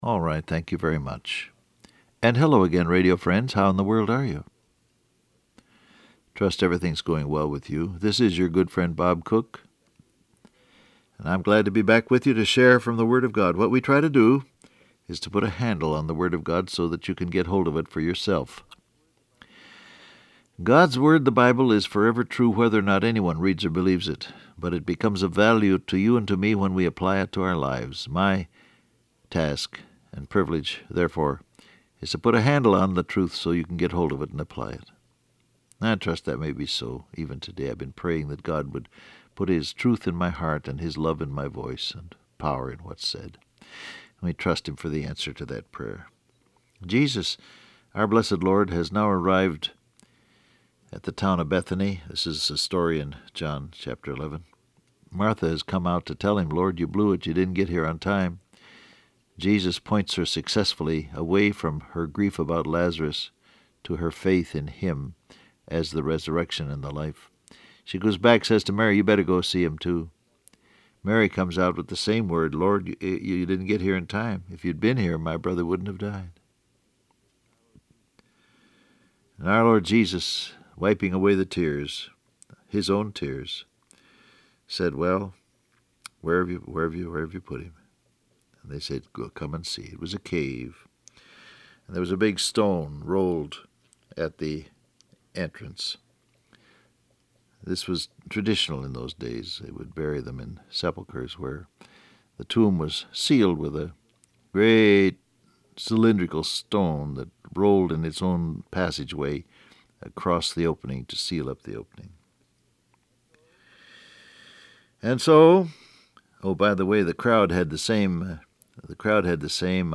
Download Audio Print Broadcast #6894 Scripture: John 11:42 Topics: Believe , Obey , Divine Innovation , Gods Blessed Will Transcript Facebook Twitter WhatsApp Alright, thank you very much.